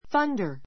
thunder θʌ́ndə r さ ンダ 名詞 雷 かみなり , 雷鳴 らいめい ⦣ 「雷のような音」の意味でも使われる.